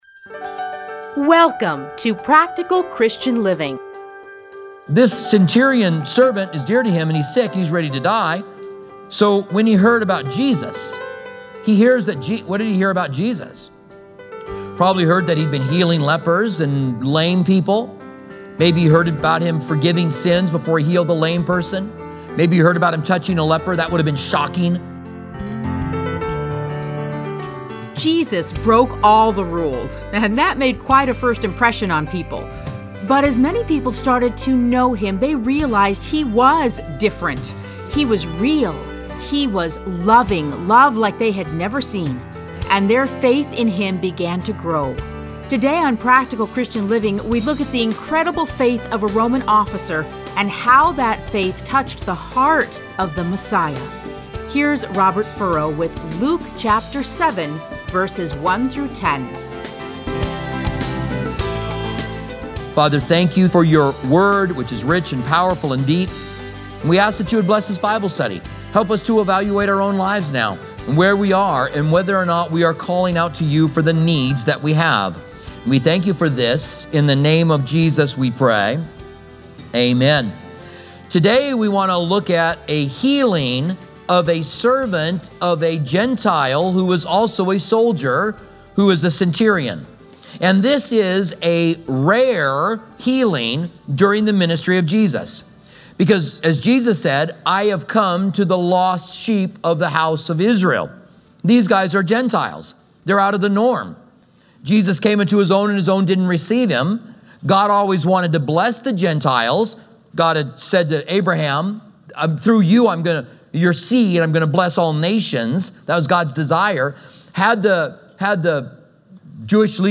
Listen to a teaching from Luke 7:1-10.